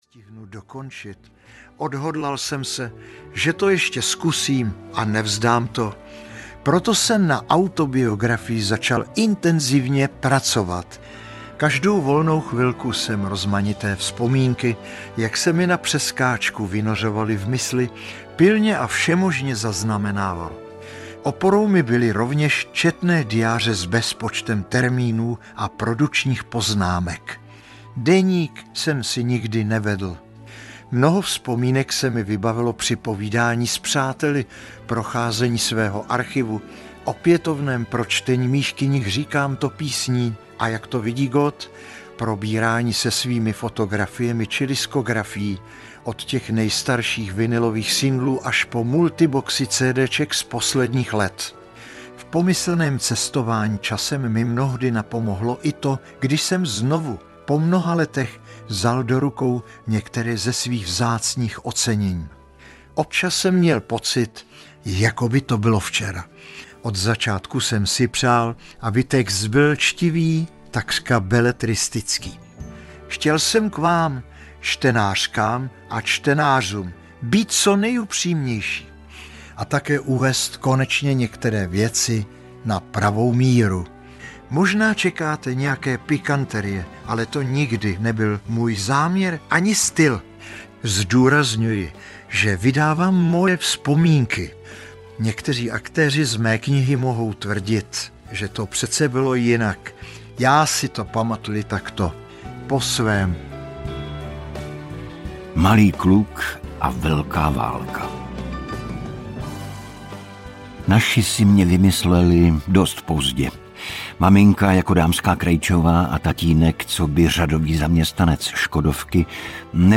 Má cesta za štěstím audiokniha
Ukázka z knihy
• InterpretIgor Bareš